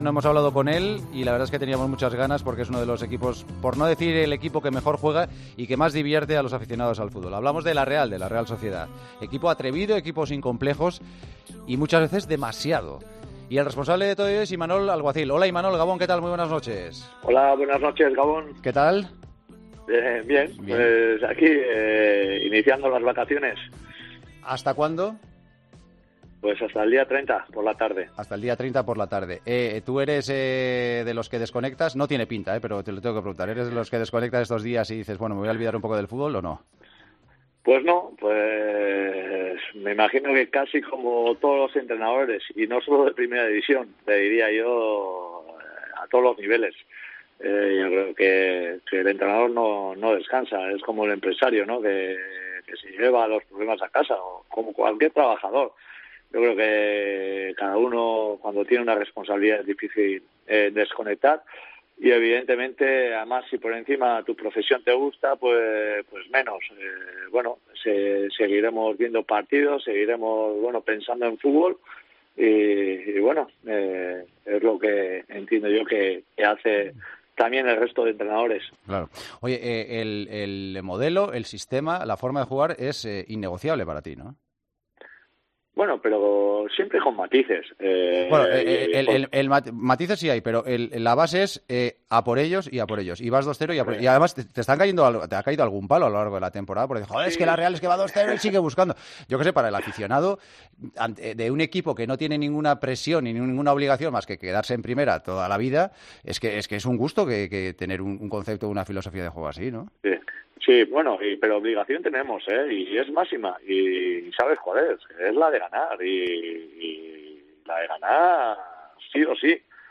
El Partidazo de COPE aprovechó este lunes las recién iniciadas vacaciones de Navidad en el fútbol español para charlar en profundidad con uno de los entrenadores revelación de lo que llevamos de temporada en LaLiga Santander: Imanol Alguacil , técnico de una Real Sociedad que ha llegado al parón en puestos europeos.